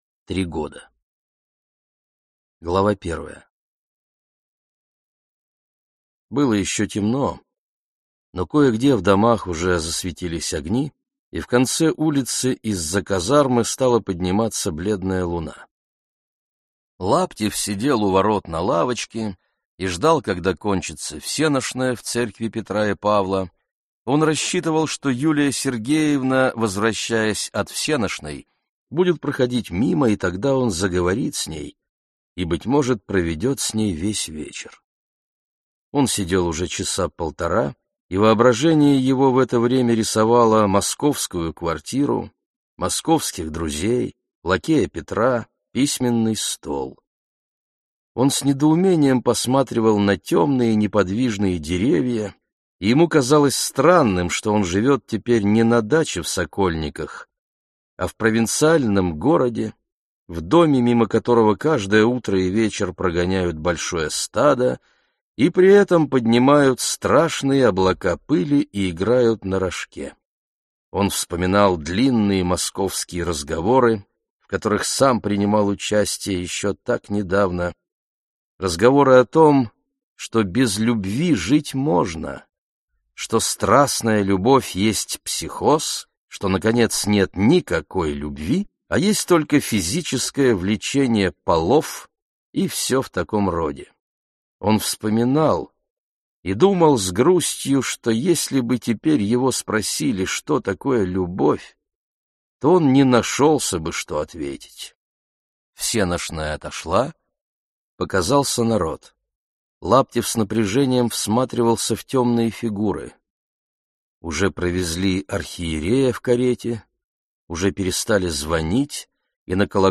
Аудиокнига Три года | Библиотека аудиокниг